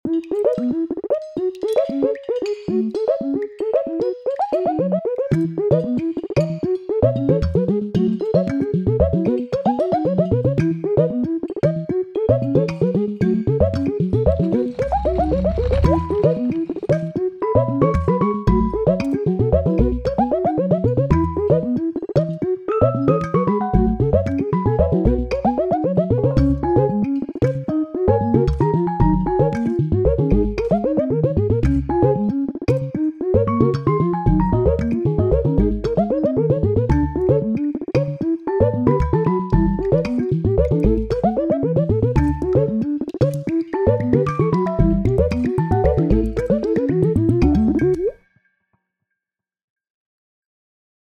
i have been making digital music for about 8 years.